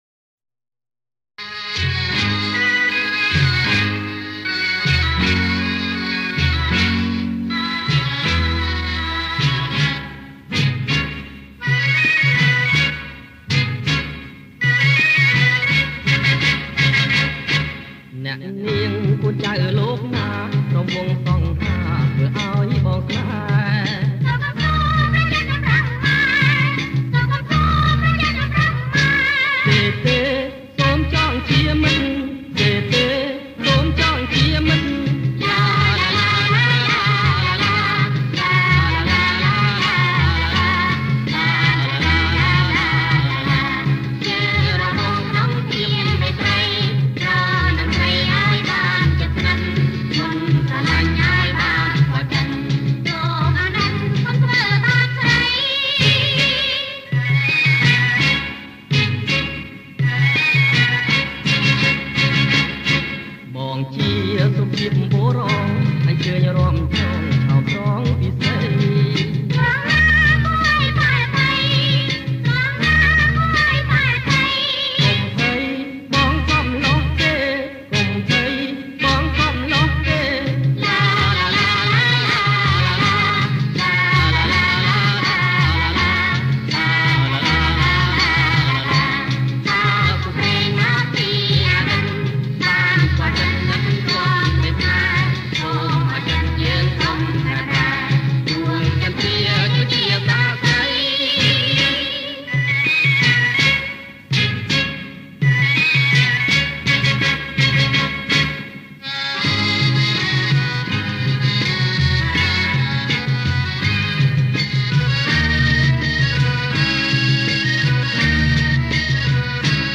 ប្រគំជាចង្វាក់ រាំវង់